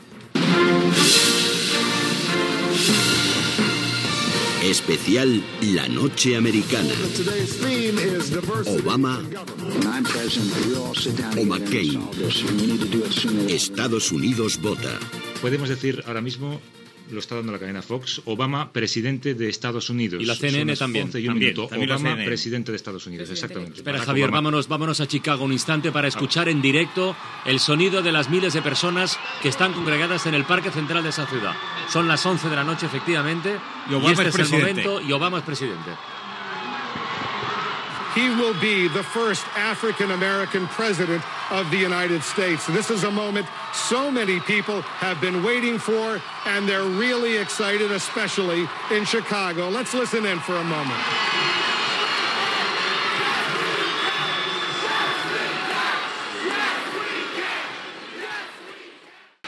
Indicatiu del programa, informació que el senador demòcrata Barack Obama serà president dels Estats Units, amb connexió amb la CNN
Informatiu